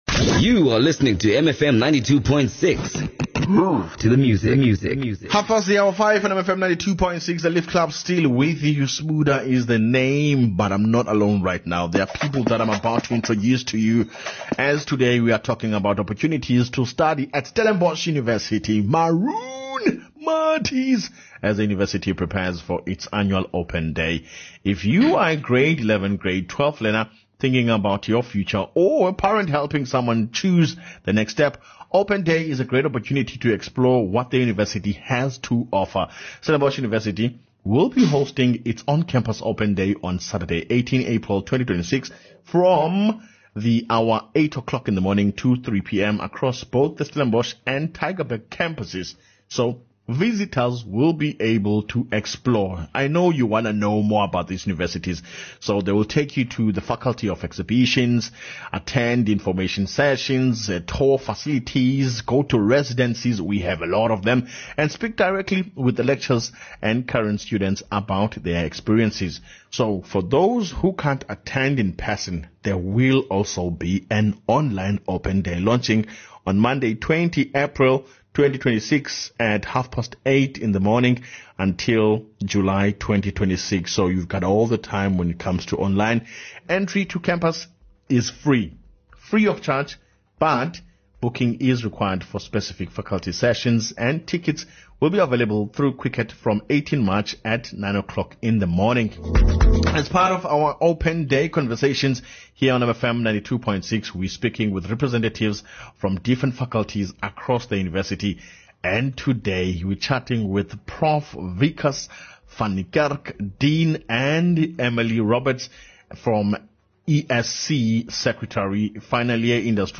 mfm-interview-openday.mp3